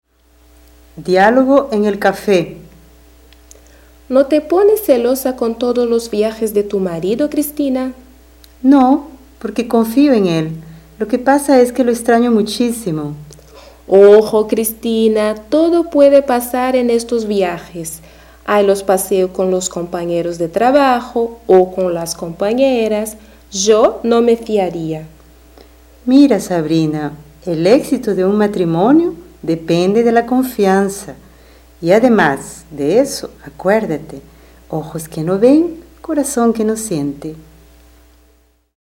Description: Áudio do livro didático Língua Espanhola I, de 2008. Diálogo com expressões populares.